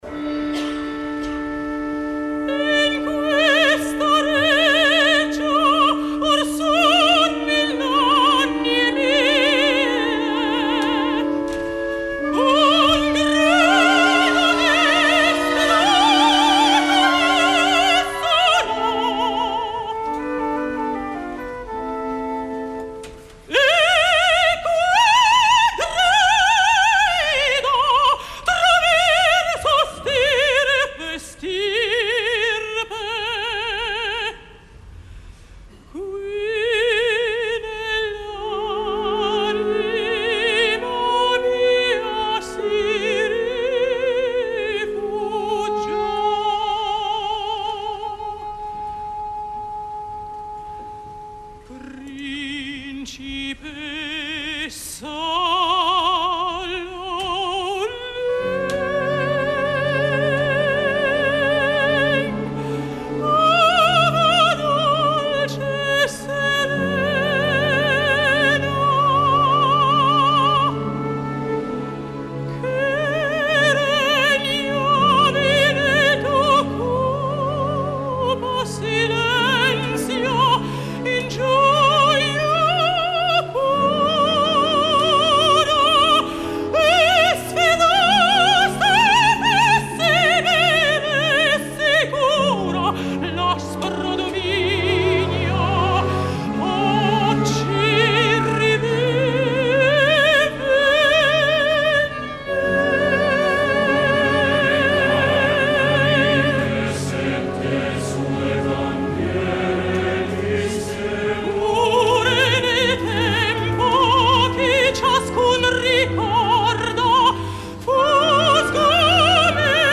Martina Serafin (Turandot a Bari, 6.12.09 Producció de Roberto De Simone)
La veu de la Serafin és molt important, però un bon volum i una projecció incisiva no és suficient per assumir aquest rol que sembla que mantindrà en la seva agenda.
És una veritable llàstima que malgasti recursos i puresa vocal, cantant la princesa xinesa musicada per Puccini , ja que si ho fa gaire sovint, acabarà per malmetre aquest instrument que encara se’ns mostra brillant, homogeni i incisiu, però que a mi em sembla, escoltant sobretot l’escena dels enigmes, que no podrà aguantar gaires excessos d’aquest tipus.
Turandot: Martina Serafin Calaf: Fabio Armiliato
Orchestra e Coro del Teatro Petruzzelli di Bari